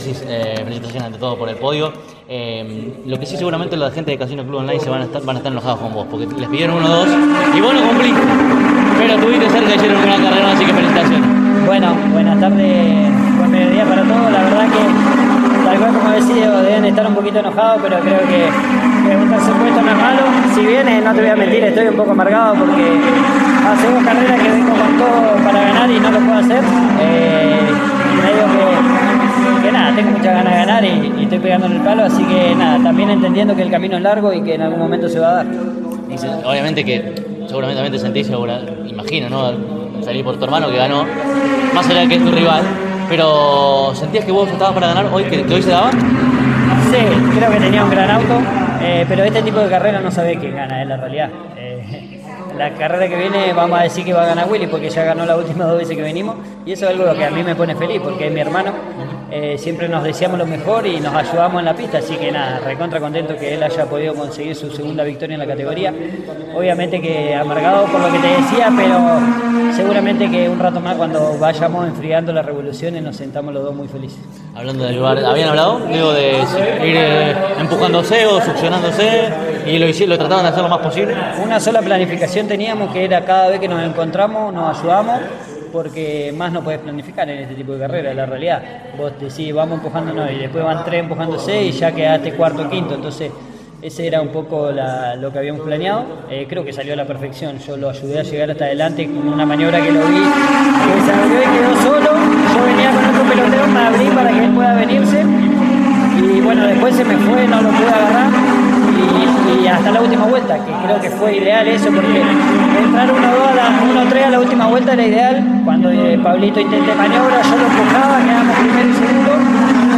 El TP disputó la segunda fecha de su calendario 2026 en el autódromo de Toay (provincia de La Pampa), y allí estuvo CÓRDOBA COMPETICIÓN.